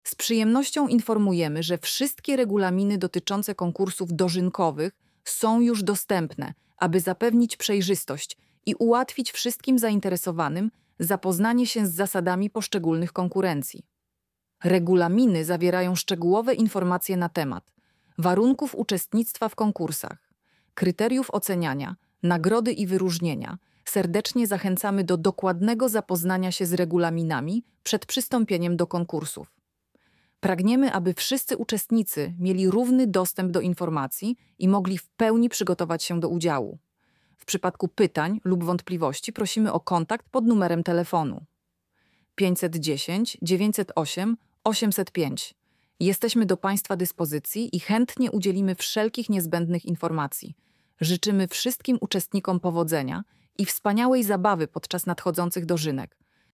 lektor-konkursy-dozynkowe.mp3